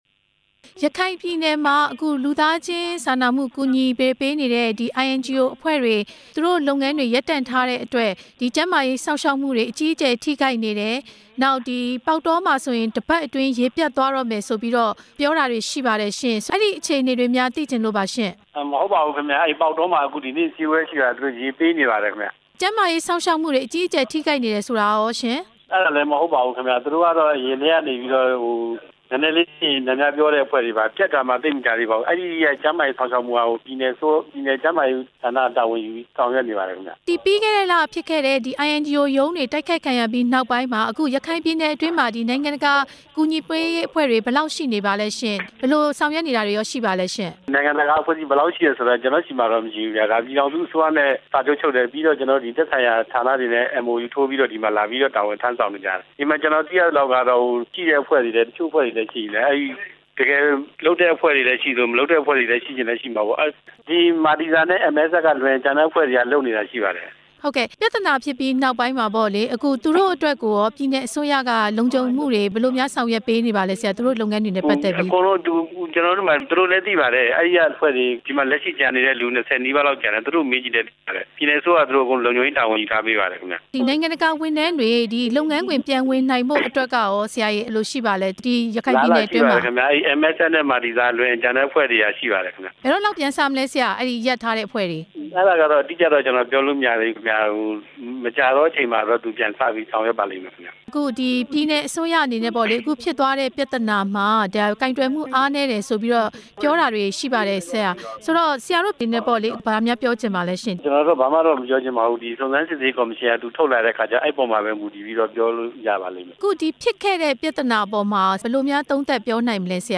ရခိုင်ပြည်နယ်က နိုင်ငံတကာ ကူညီပေးရေးလုပ်ငန်းတွေ ရပ်ဆိုင်းခဲ့တဲ့အကြောင်း မေးမြန်းချက်